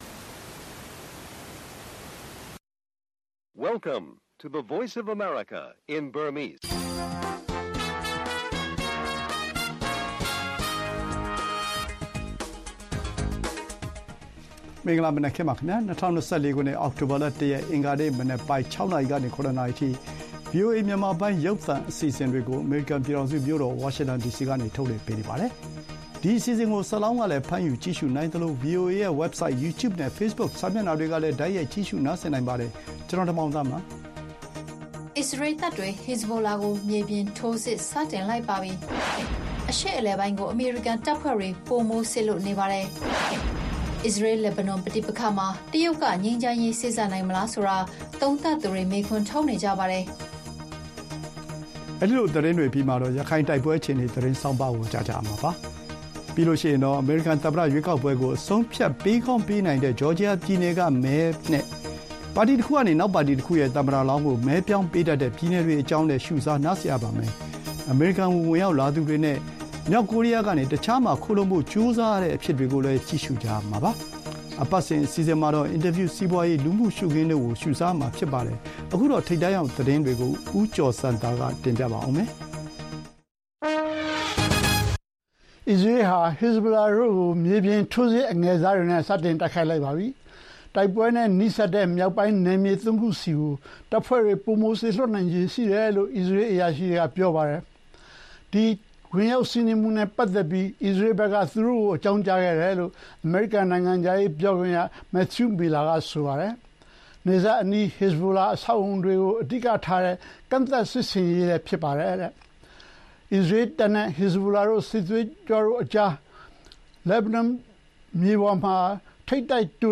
အစ္စရေးတပ်တွေ Hezbollah ကို မြေပြင်ထိုးစစ်စတင်၊ အရှေ့အလယ်ပိုင်းမှာ အမေရိကန်တပ်ထပ်တိုး၊ အစ္စရေး-လက်ဘနွန် ပဋိပက္ခမှာ တရုတ်ကငြိမ်းချမ်းရေးစေ့စပ်နိုင်မလားစတဲ့သတင်းတွေနဲ့အတူမြန်မာ့အရေးမှာ ကုလသမဂ္ဂရဲ့ အခန်းကဏ္ဍနဲ့ ပတ်သက်တဲ့ မေးမြန်းခန်း၊ စီးပွားရေး၊ လူမှုရှုခင်း သီတင်းပတ်စဉ် အစီအစဉ်တွေကို တင်ဆက်ထားပါတယ်။